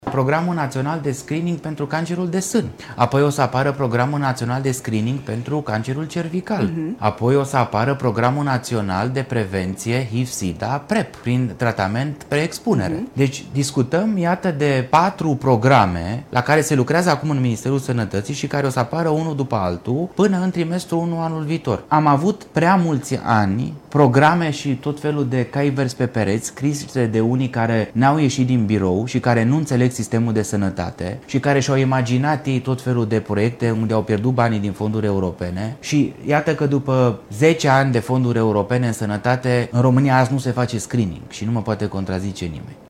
Ministrul Sănătății, Alexandru Rogobete: „ Am avut prea mulți ani programe și tot felul de «cai verzi pe pereți», scrise de unii care n-au ieșit din birou și care nu înțeleg sistemul”